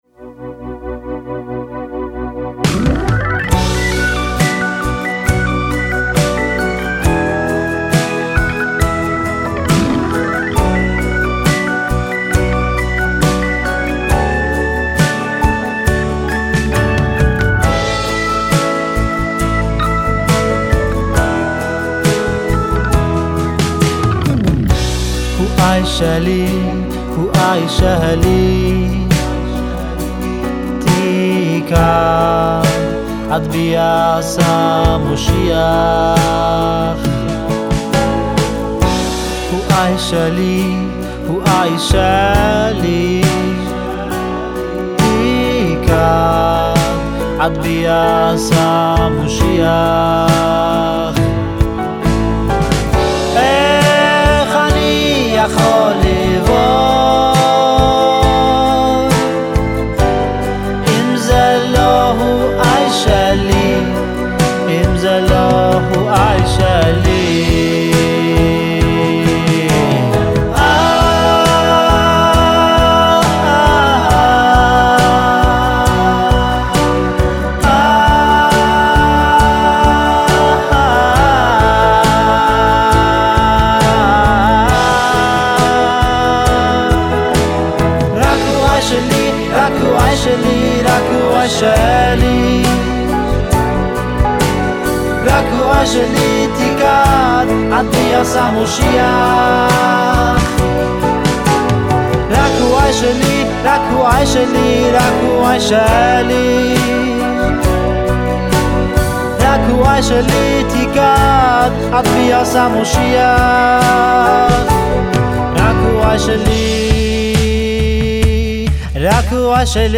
מגיח שוב עם לחן סוחף
שירתו ולחניו רגועים ומלאי רגש.
בסגנון רוק איטי